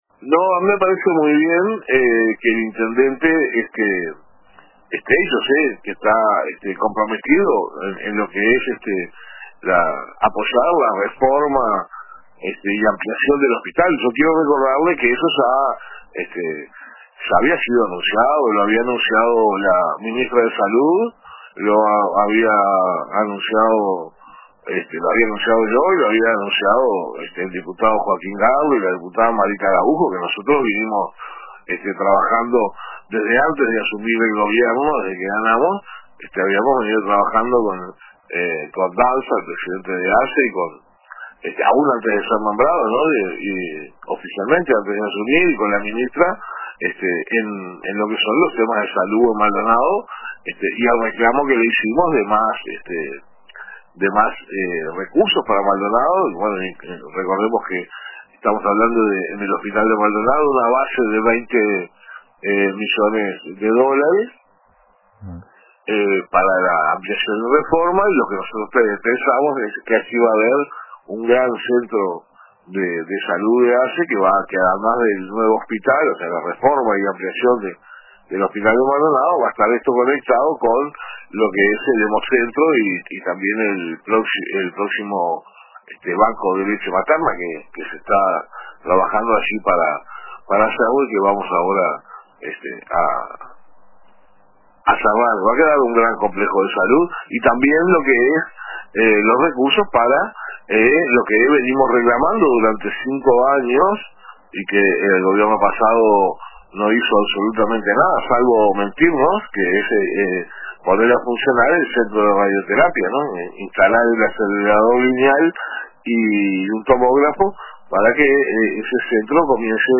En declaraciones al programa “Radio con Todo” de RBC, Antonini señaló que el plan de obras contempla una inversión de 20 millones de dólares destinada a la ampliación y modernización del hospital, que formará parte de un complejo sanitario integral de ASSE.